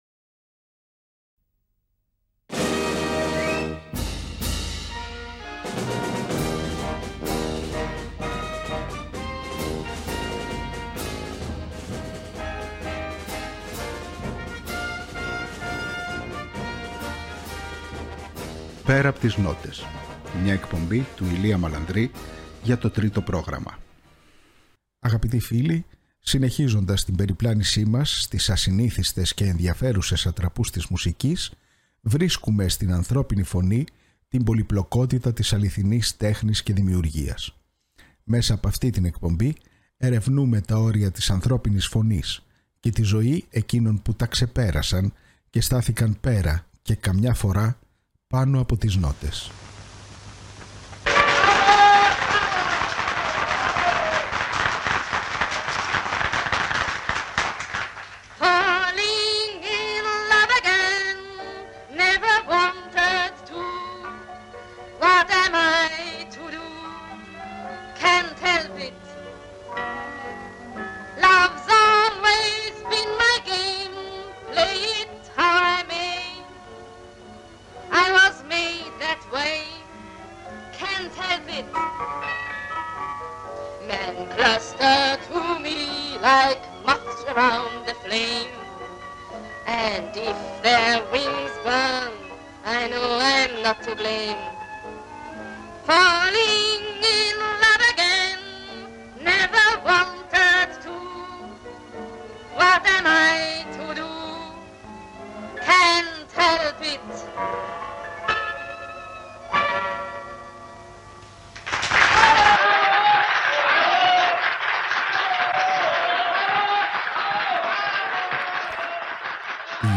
Μέσα από σπάνιο ηχητικό αρχείο συνεντεύξεων και άγνωστων ανέκδοτων ηχογραφήσεων ξετυλίγονται τα Πορτραίτα 30 καλλιτεχνών που άφησαν ένα τόσο ηχηρό στίγμα στην τέχνη καταφέρνοντας να γίνουν σημείο αναφοράς και να εγγραφούν στην ιστορική μνήμη, όχι μόνο ως ερμηνευτές αλλά και ως σύμβολα.